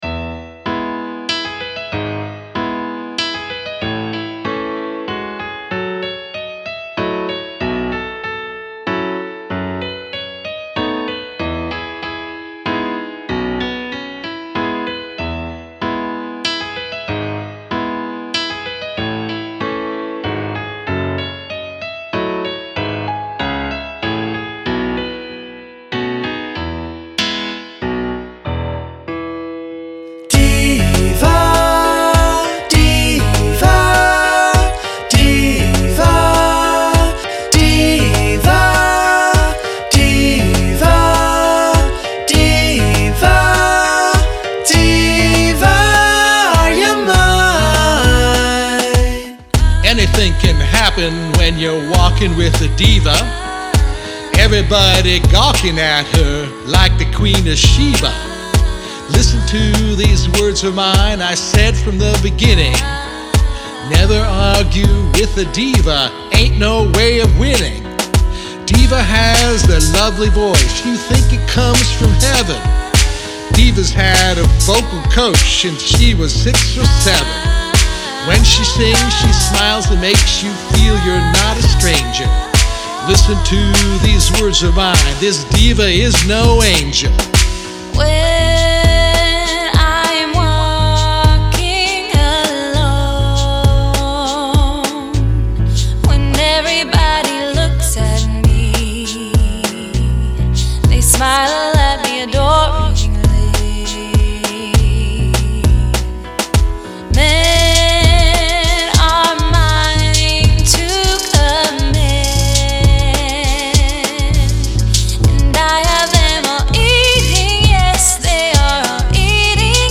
an upbeat Hip Hop song
The style transformed to Hip Hop.